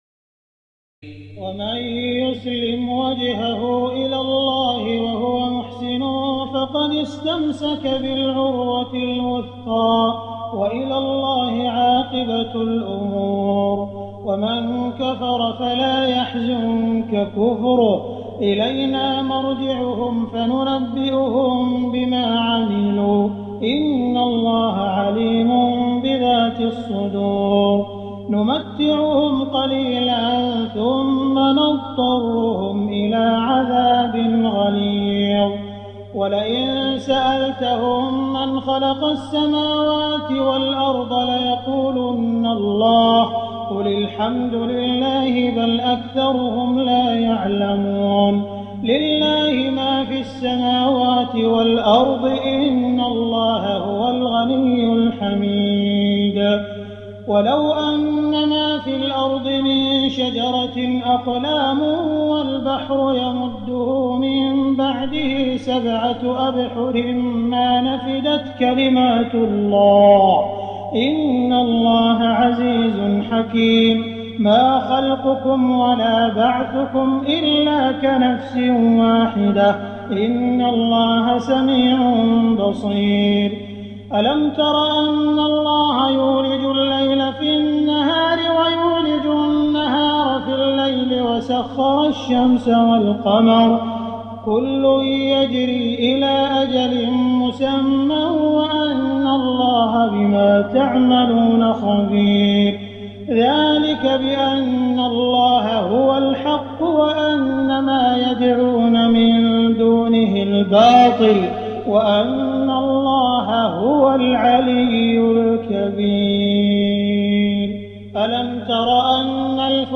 تراويح الليلة العشرون رمضان 1419هـ من سور لقمان (22-34) والسجدة و الأحزاب(1-34) Taraweeh 20 st night Ramadan 1419H from Surah Luqman and As-Sajda and Al-Ahzaab > تراويح الحرم المكي عام 1419 🕋 > التراويح - تلاوات الحرمين